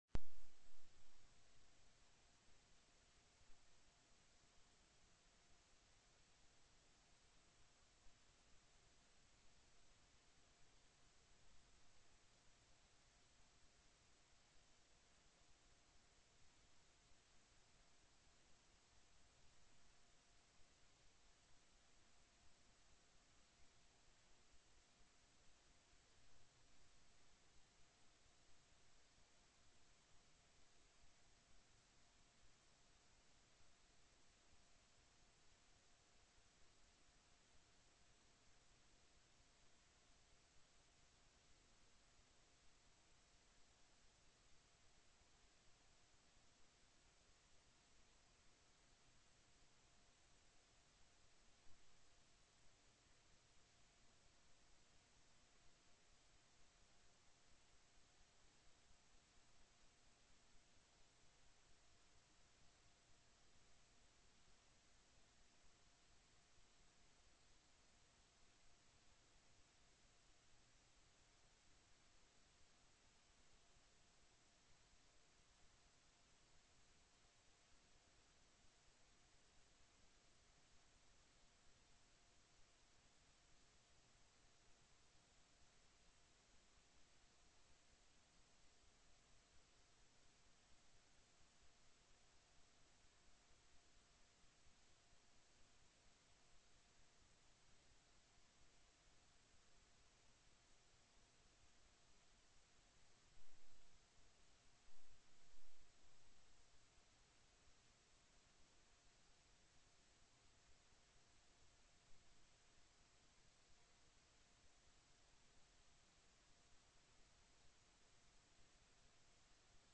03/13/2008 09:00 AM Senate FINANCE
SENATE FINANCE COMMITTEE March 13, 2008 9:36 a.m.